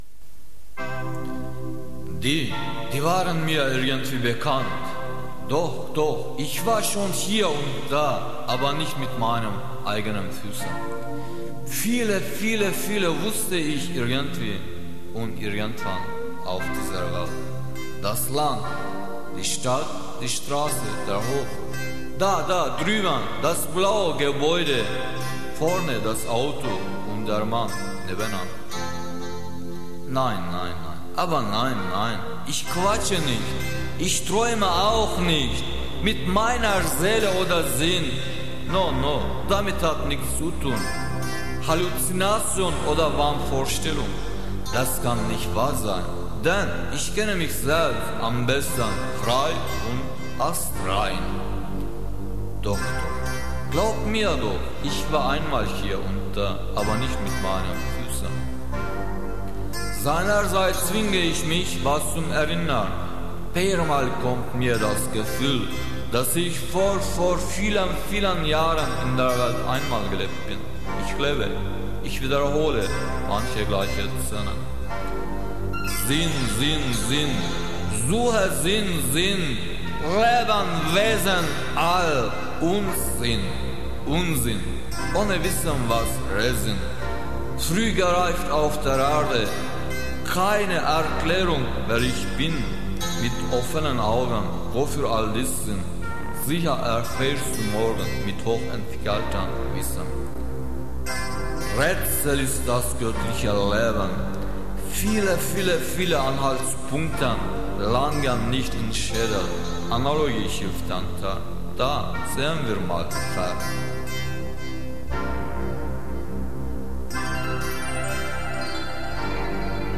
Hızlı ve yüksek sesli Pop-Rockt' tan (bağrı-çağrı).
Çalgı sesleri (Enstrümantal) genelde bilgisayar destekli
Hacimsel enstrümantal sesler arka plandan yansıtılarak